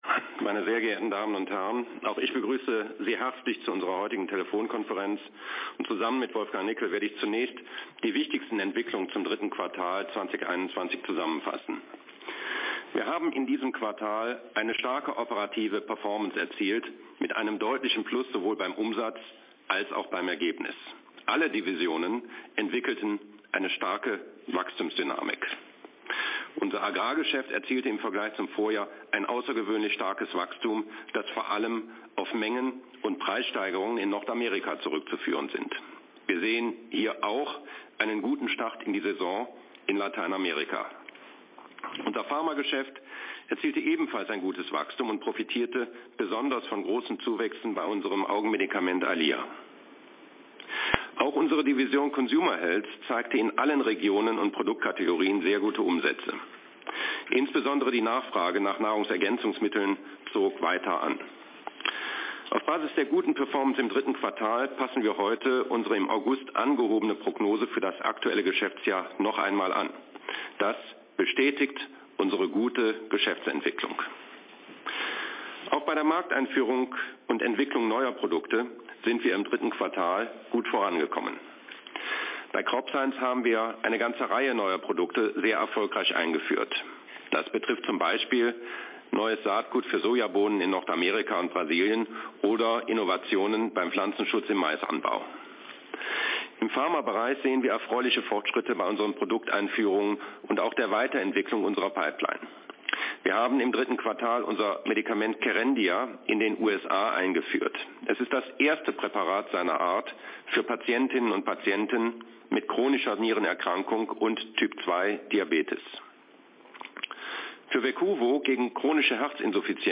Telefon-Pressekonferenz der Bayer AG